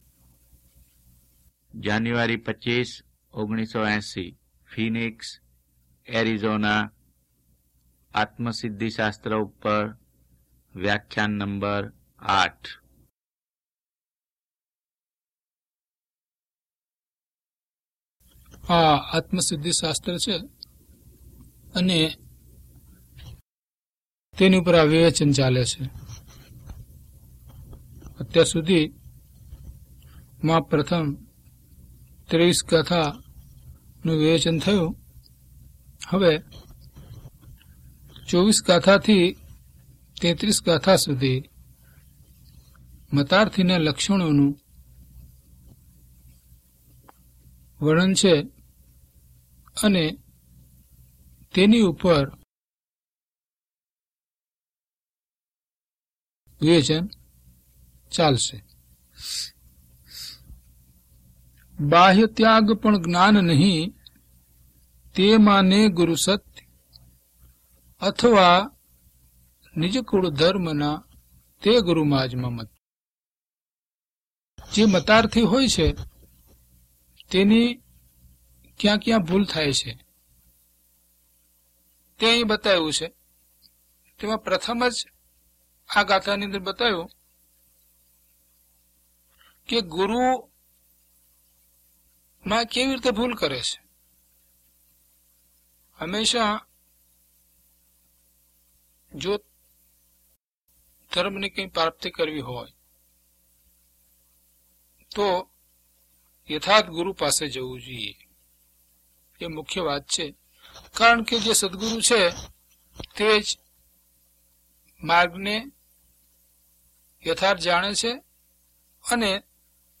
DHP017 Atmasiddhi Vivechan 8 - Pravachan.mp3